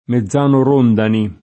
mezzano [mezz#no] agg. e s. m. — sim. i top. e cogn. Mezzani, Mezzano — tra i top.: Mezzano [mezz#no] comune in Val Cismon (Trent.), Mezzano Siccomario [mezz#no Sikkom#rLo] (Lomb.), Mezzano Rondani [